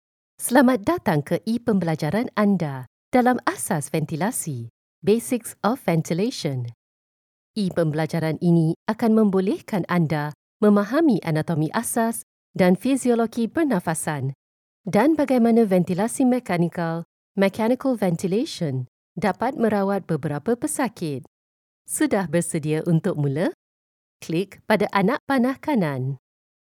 E-learning
Microfone Scarlett, Interface, Bloqueador de Pop, Mixer Behringer
BarítonoGravesProfundoBaixo
AbafadoSedutorConversacionalPersuasivoNeutro